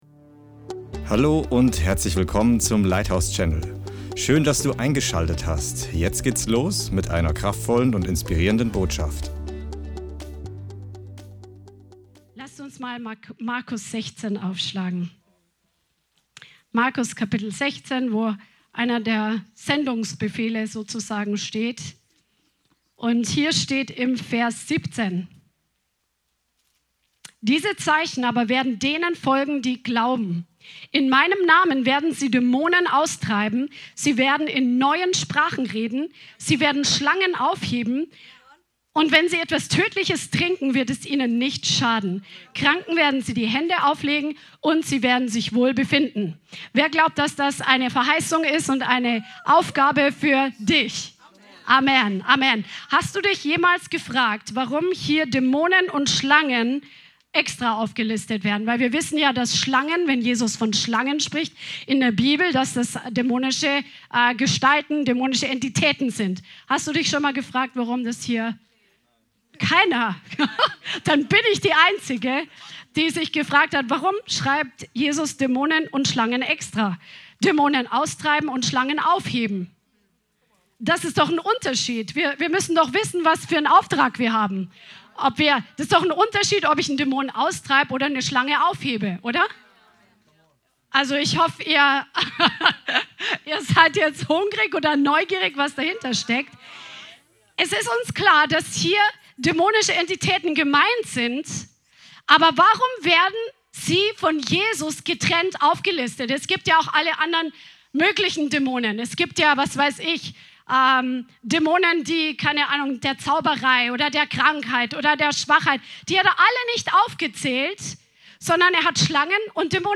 Lerne in dieser Predigt, wie du mit den geistlichen Werkzeugen aus der Bibel die dämonische Schlangenattacken überwinden kannst.